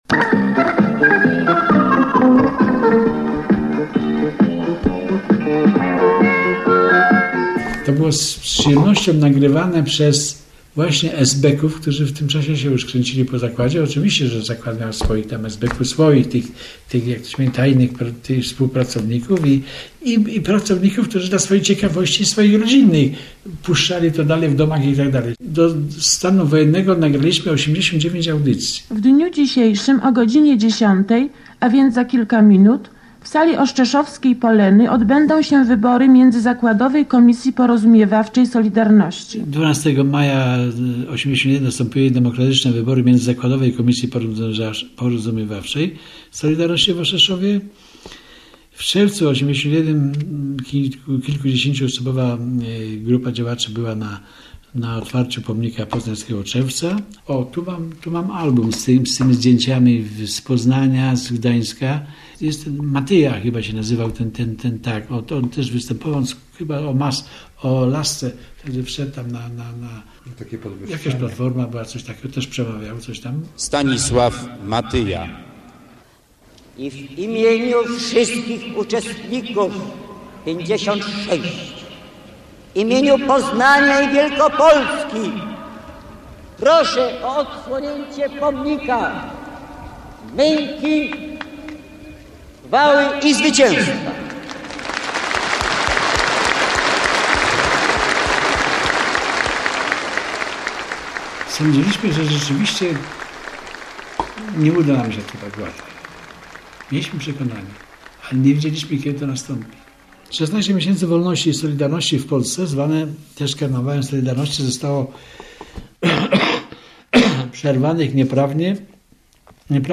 Oto reportaż